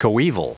Prononciation du mot coeval en anglais (fichier audio)
Prononciation du mot : coeval